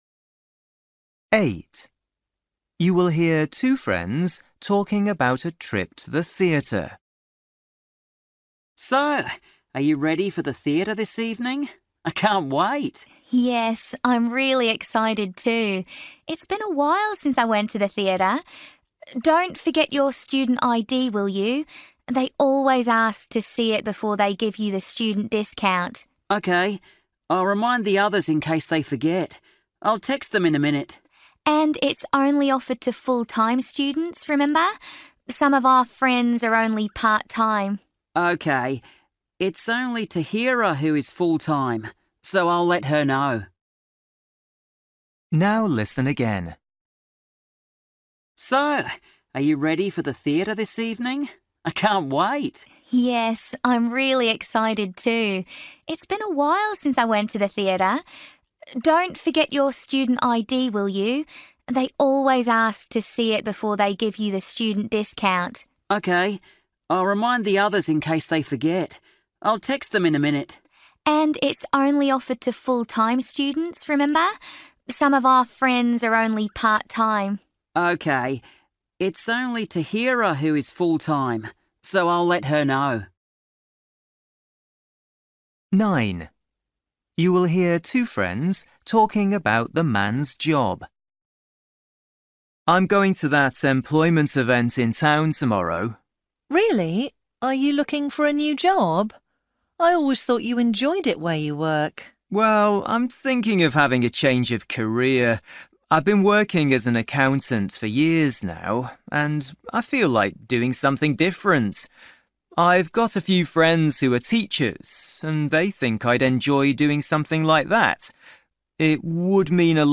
Listening: everyday short conversations
8   You will hear two friends talking about a trip to the theatre. What does the woman say about it?
10   You will hear a husband and wife talking about breakfast. What does the wife say?
12   You will hear two friends talking about going for a walk. What do they both agree about?